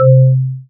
Now listen to the combination of all three frequencies (simulated C3 marimba bar):
130.81+523.25+1318.5 Hz